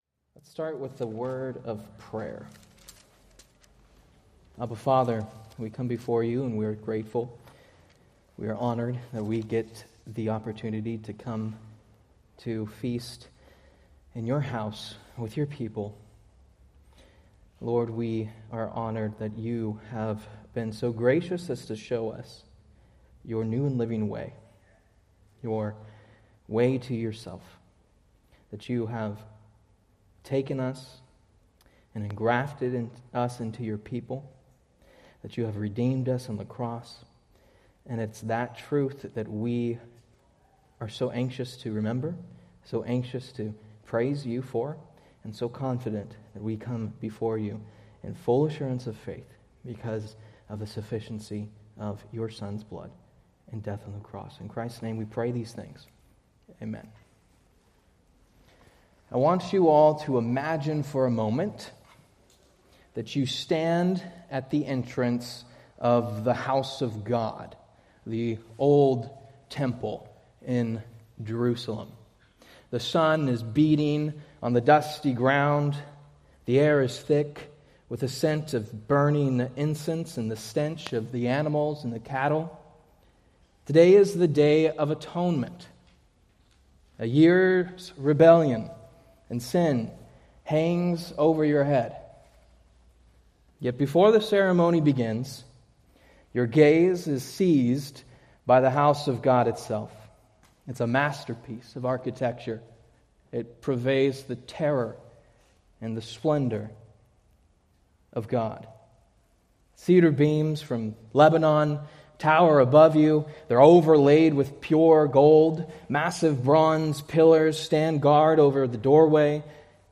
Date: Nov 2, 2025 Series: Various Sunday School Grouping: Sunday School (Adult) More: Download MP3